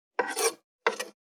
575肉切りナイフ,まな板の上,包丁,ナイフ,調理音,料理,
効果音厨房/台所/レストラン/kitchen食器食材